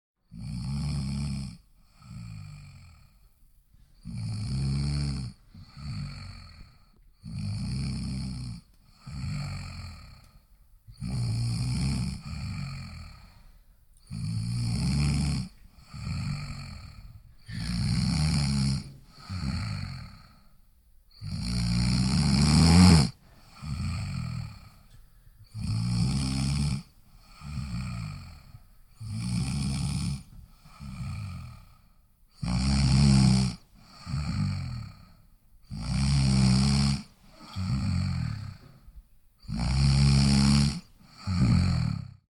Звуки храпа
Тихий храп зрелого мужчины